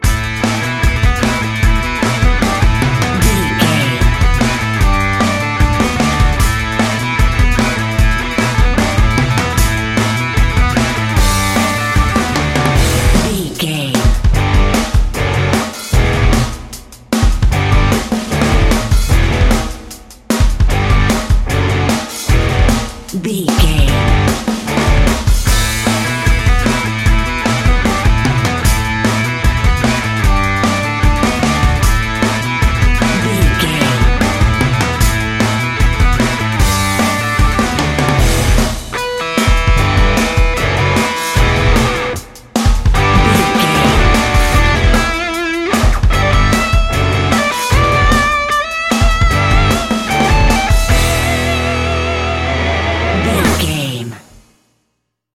Aeolian/Minor
Fast
drums
electric guitar
bass guitar
hard rock
lead guitar
aggressive
energetic
intense
nu metal
alternative metal